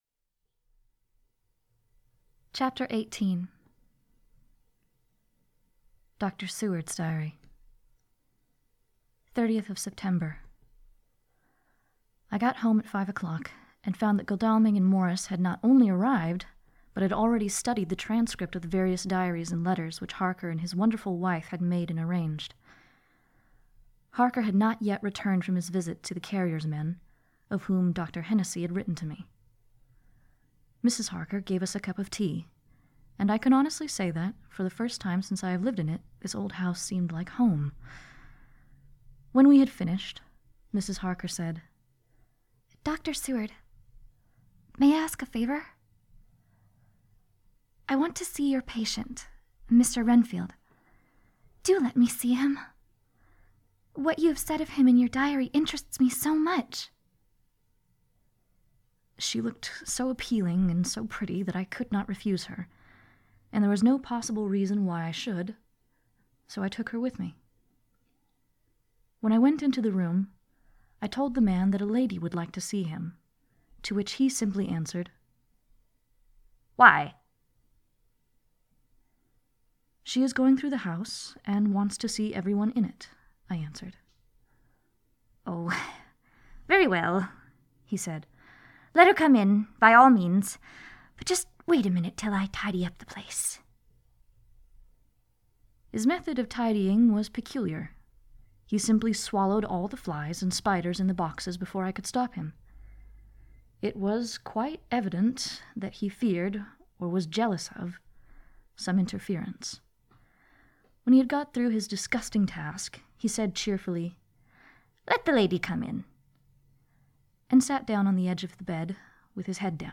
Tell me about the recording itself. Recorded live on Discord on 2/15!